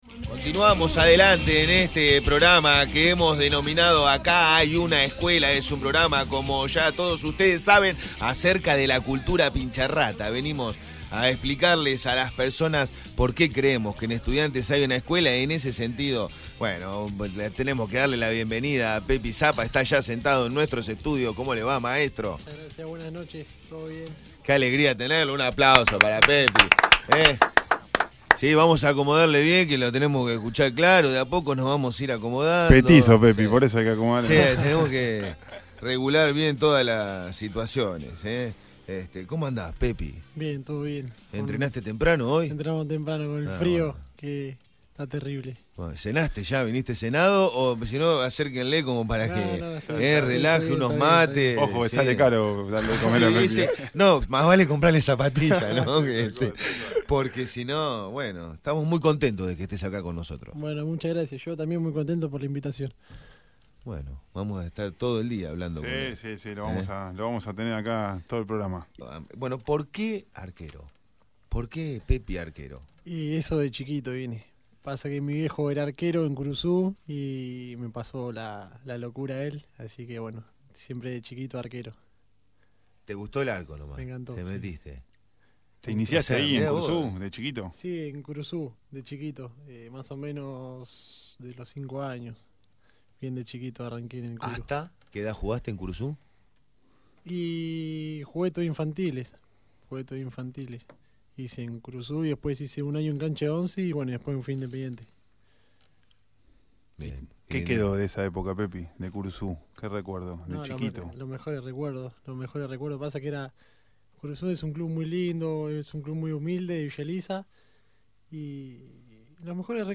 Anécdotas, recuerdos y mucho más. Escuchá la entrevista completa